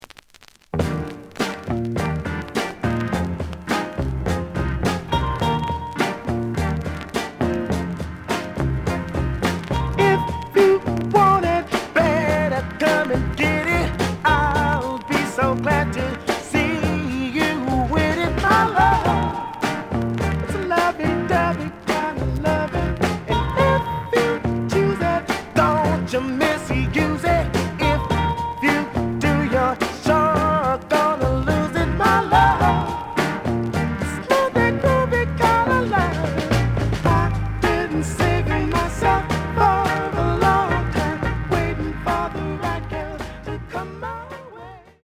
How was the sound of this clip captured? The audio sample is recorded from the actual item. ●Format: 7 inch Slight edge warp.